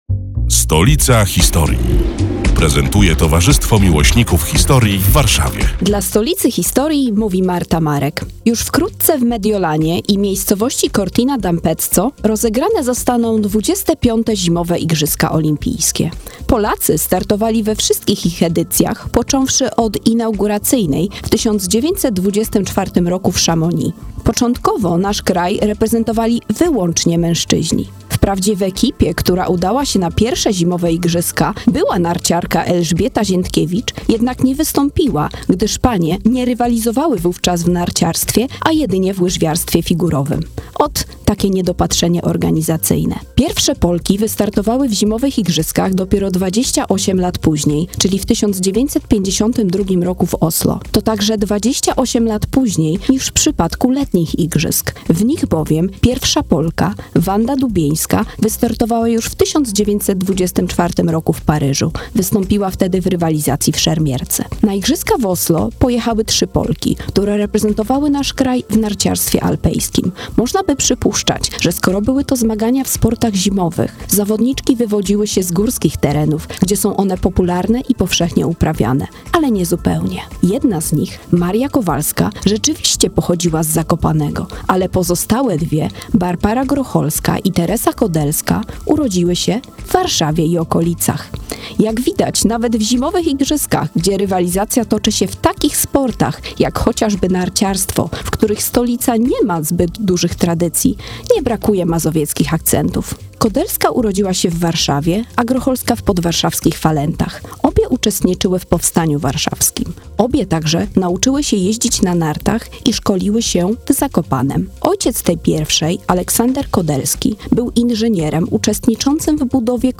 142. felieton pod wspólną nazwą: Stolica historii. Przedstawiają członkowie Towarzystwa Miłośników Historii w Warszawie, które są już od trzech lat emitowane w każdą sobotę, w nieco skróconej wersji, w Radiu Kolor.